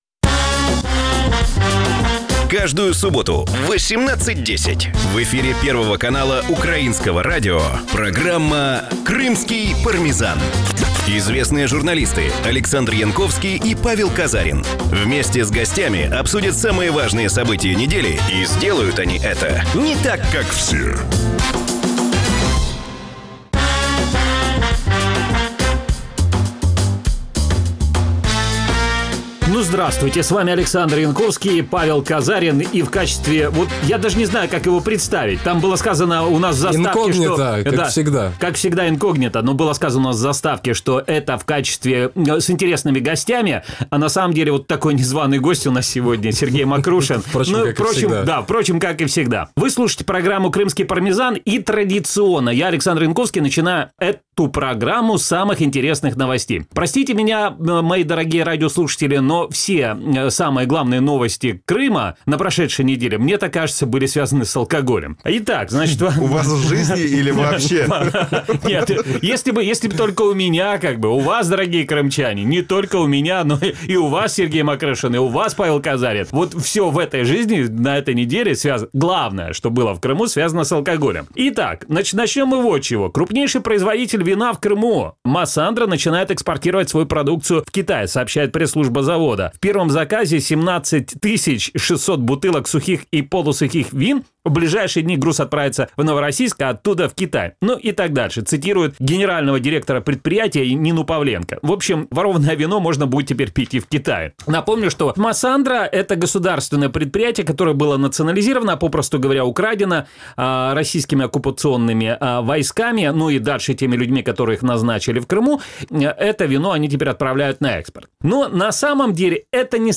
За що російська поліція б'є кримчан і туристів в кримських містах, яка російська авіакомпанія збанкрутує наступної і як кримському студенту вступити до українського ВУЗу – відповіді на ці питання в нашій програмі. Програма звучить в ефірі Радіо Крим.Реаліі. Це новий, особливий формат радіо.